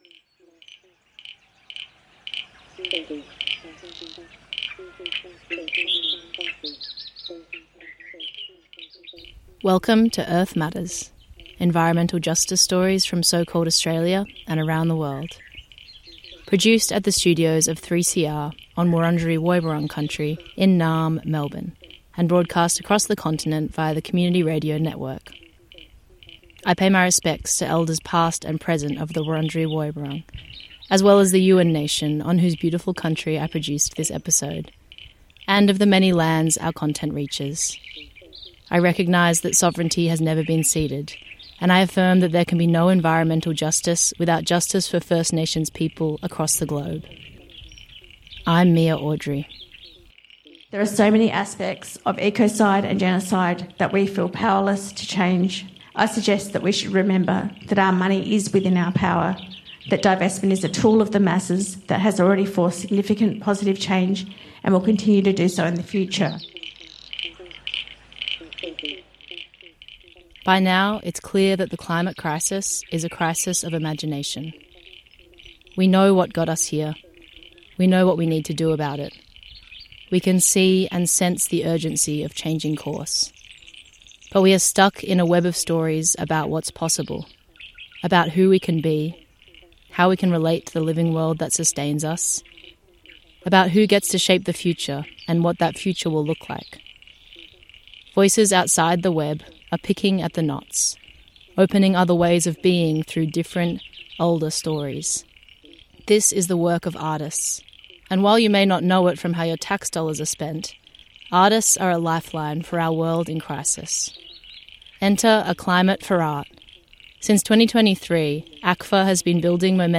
This show features two recordings from a Symposium hosted by ACFA—in partnership with Next Wave, City of Melbourne, George Paton Gallery and Climate Action Network Australia—which together give a glimpse of how our biggest imaginations are responding to the climate crisis.